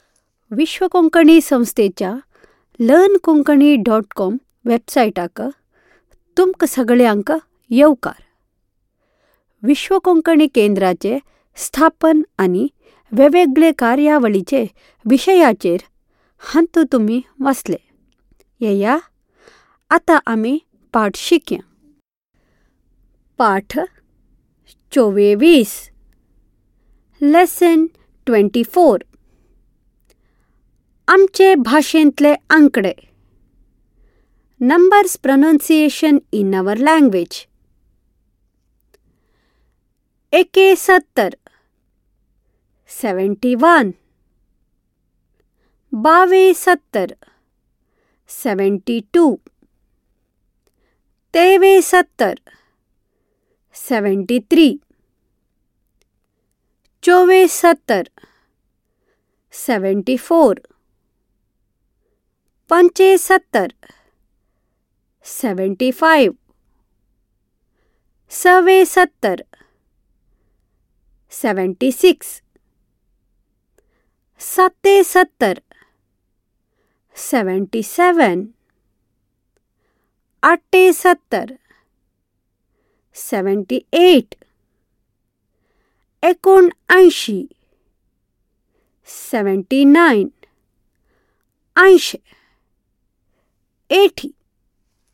आमचे भाशेंतले आंकडे Aamche Bhashentle Ankade Numbers pronunciation in our language